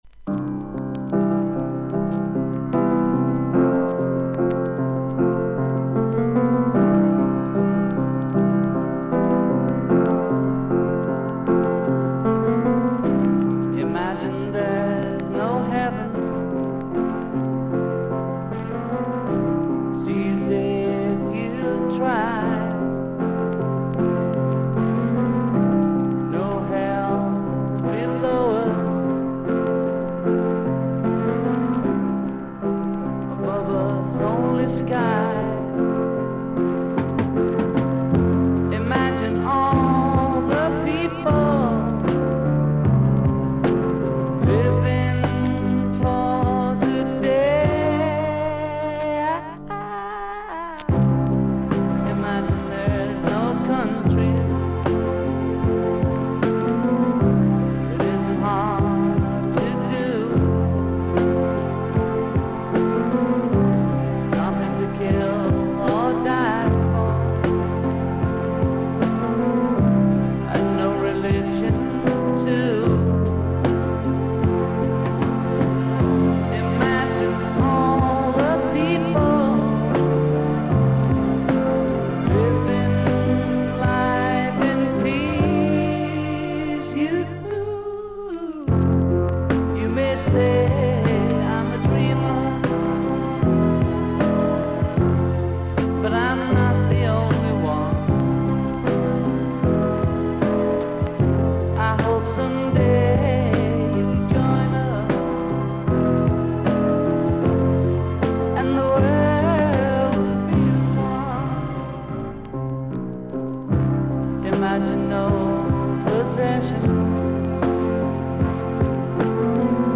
A1序盤から中盤に9mmほどのキズ、少々周回ノイズがあります。
少々サーフィス・ノイズあり。クリアな音です。